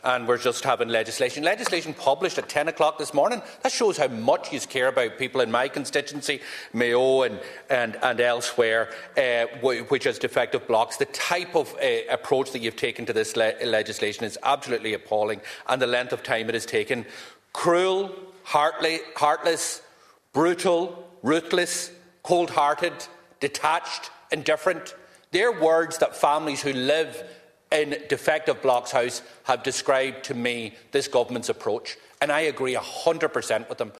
The second stage of the bill introducing amendments to Defective Concrete Block redress legislation were carried out in the Dáil chamber yesterday evening, with Donegal TDs having their voices heard.
Speaking in the Dáil Chamber yesterday evening, Deputy Pearse Doherty did not mince his words as to his feelings about the timing..………